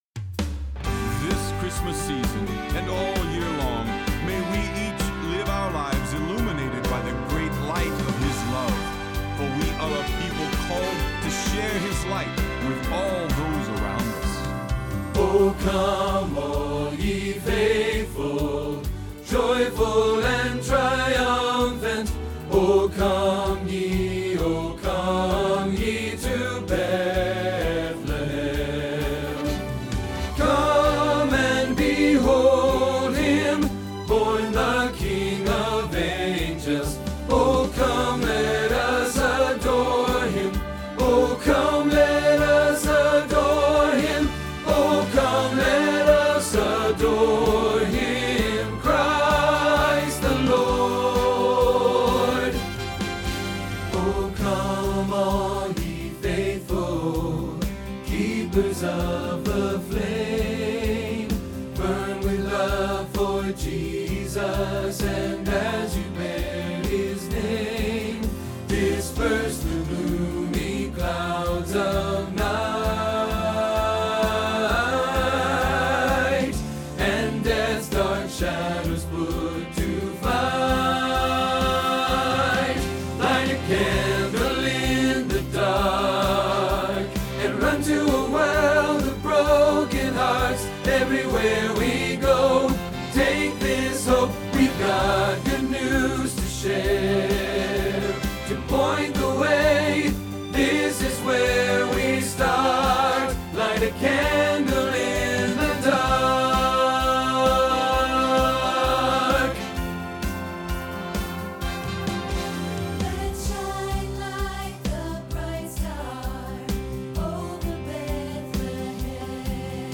Tenor Practice Trax